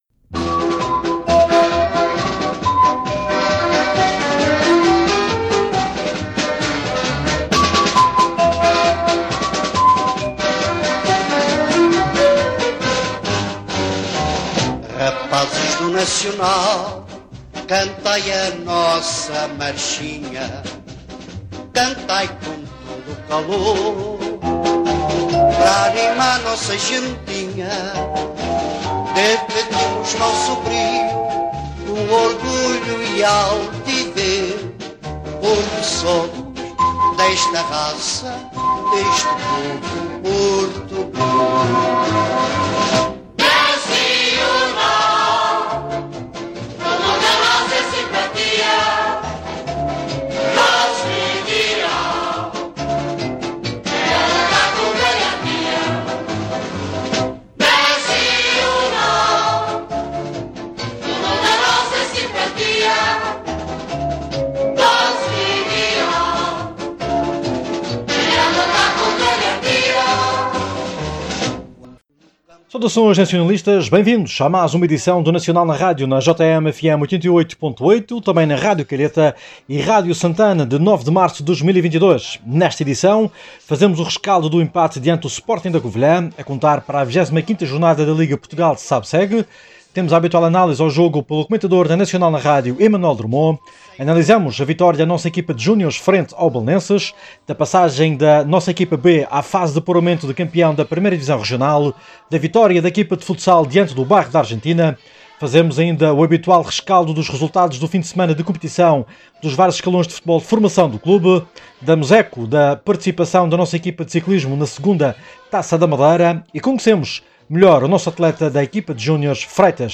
Na edição desta quarta-feira do programa ‘Nacional na Rádio’, emitido na JM/FM 88.8 e também nas Rádio Calheta Santana